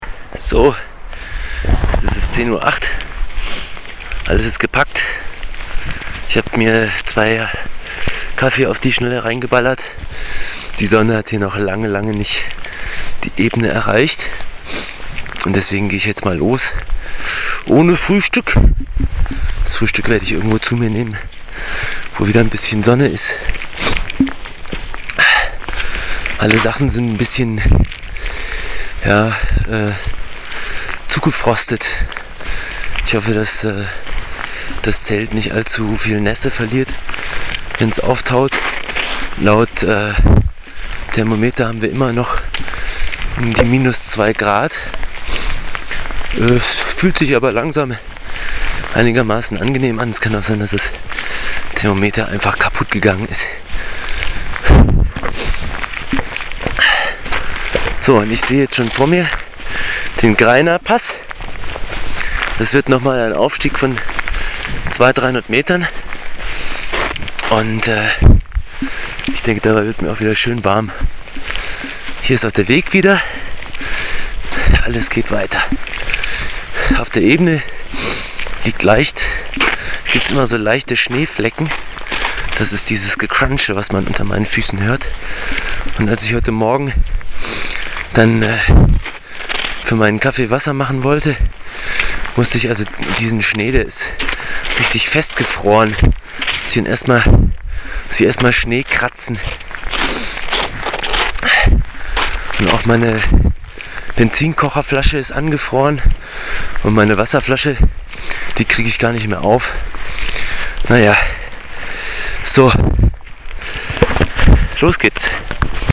Ein kleiner Audiobericht meiner Alpenpassüberquerung mit Zelt und Krempel.
Diesrutpass auf 2400m